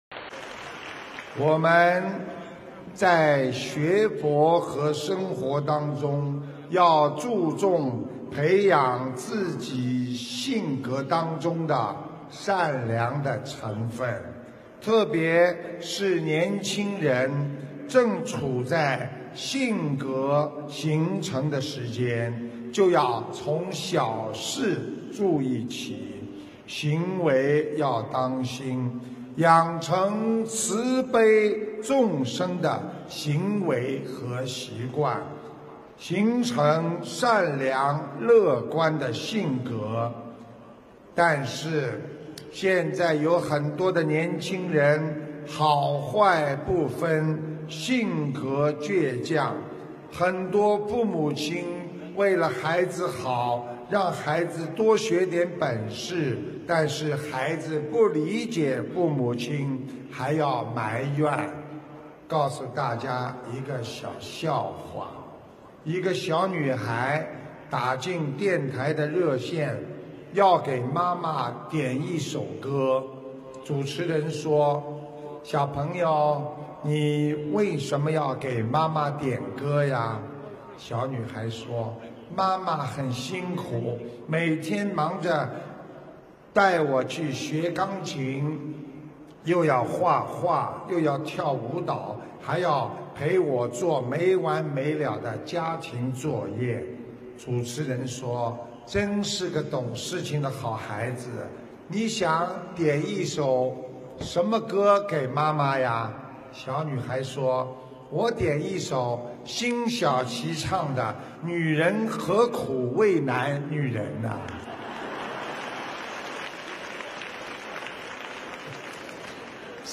音频：女人何苦为难女人·师父讲笑话